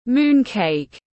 Bánh trung thu tiếng anh gọi là moon cake, phiên âm tiếng anh đọc là /ˈmuːn keɪk/